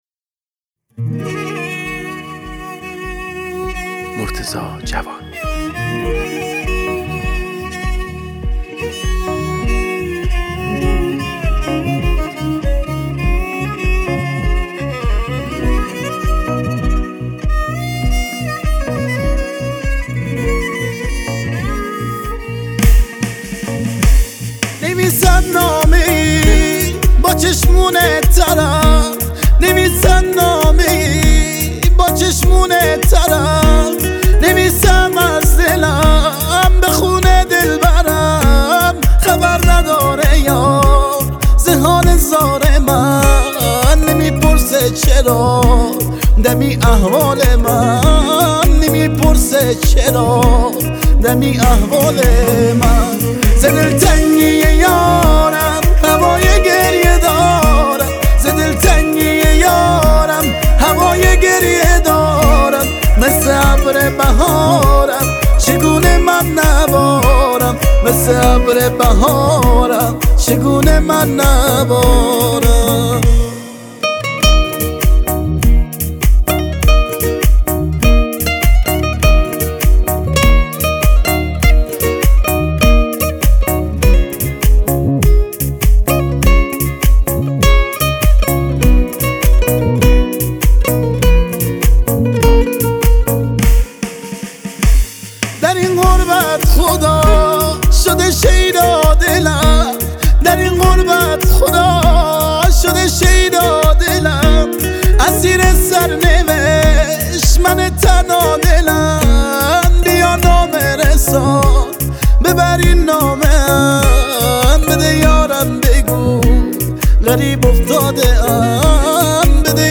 نسخه تک خوانی